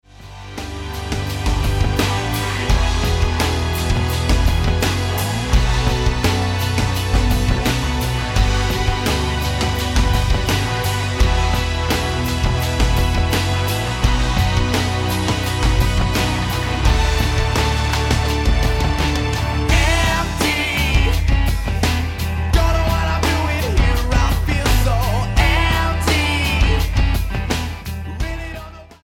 7836 Style: Rock Approach